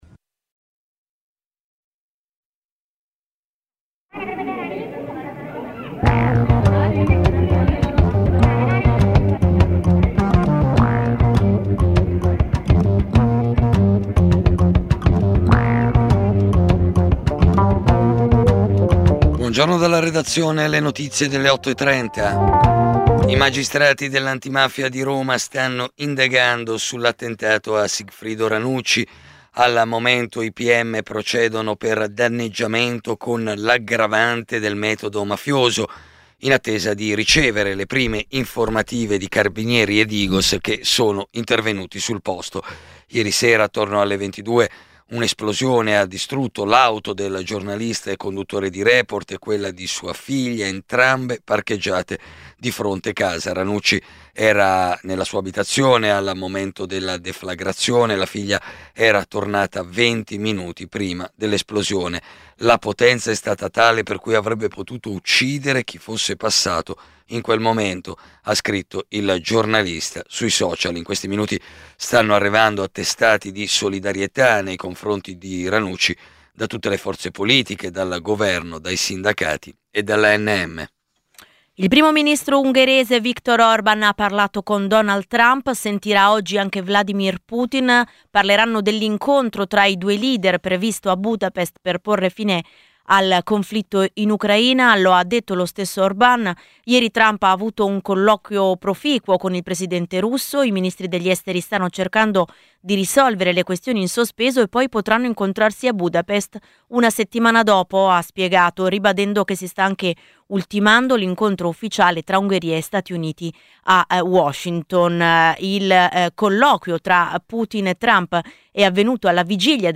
Giornale radio nazionale - del 17/10/2025 ore 08:30